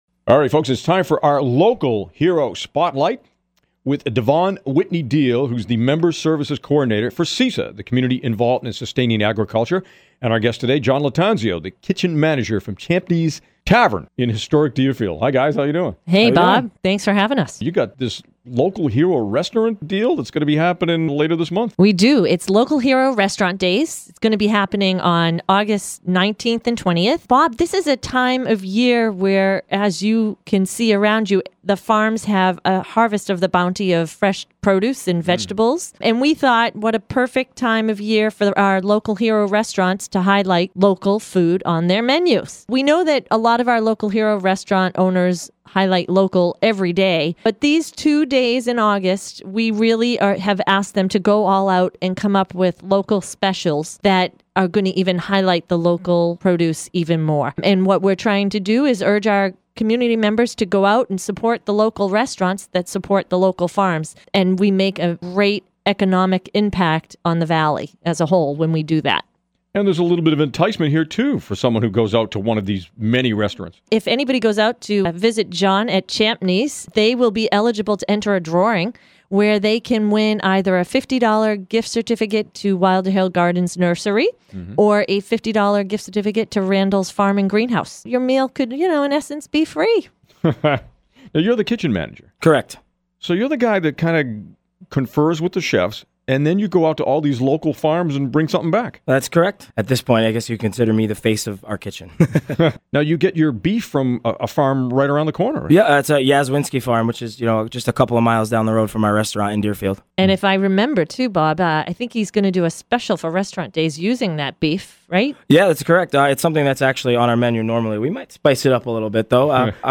Interview: Champney’s Restaurant